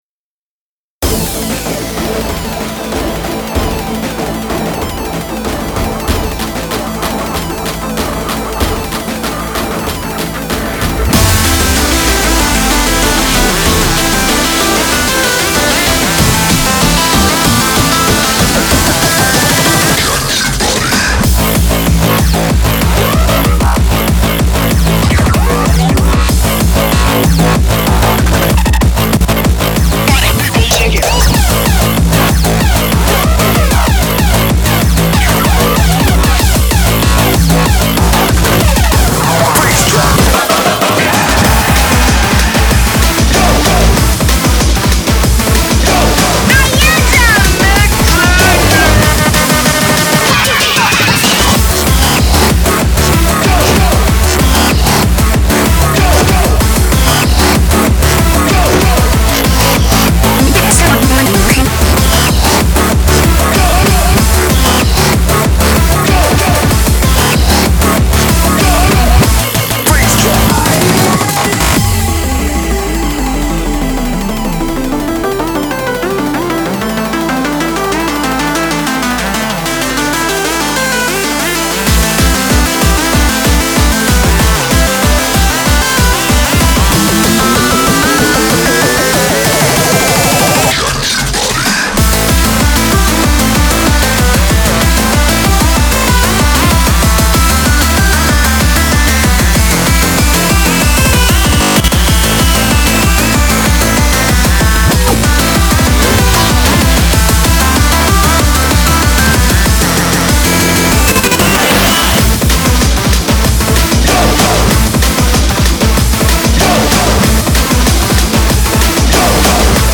BPM95-190